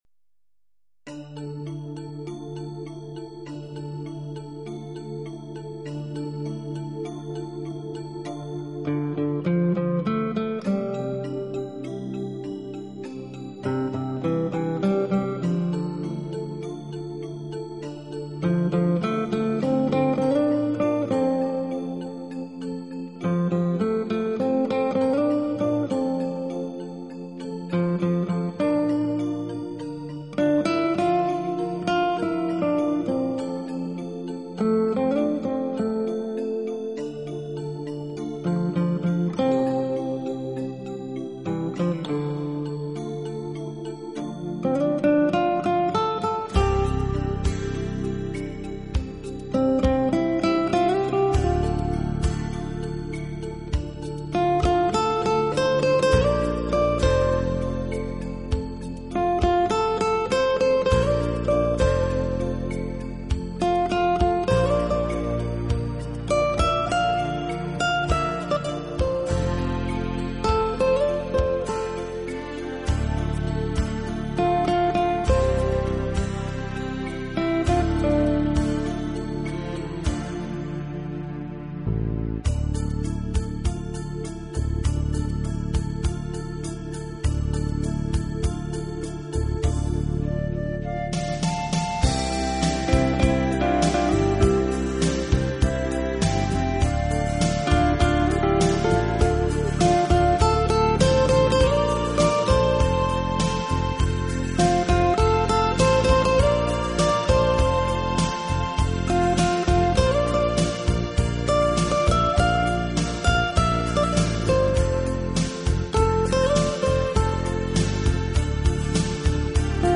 Romantic Guitar|MP3|320Kbps|1990